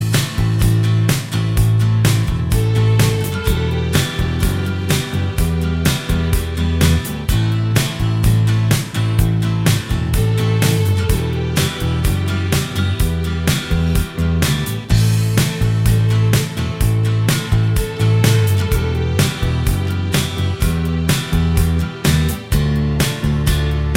Minus Mandolin Indie / Alternative 4:27 Buy £1.50